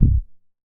MoogKilo 004.WAV